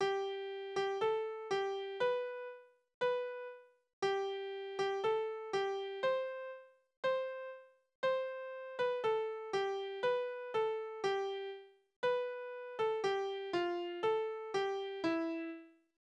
Tanzverse: Kreuzpolka
Tonart: C-Dur
Taktart: 4/4
Tonumfang: Oktave
Besetzung: vokal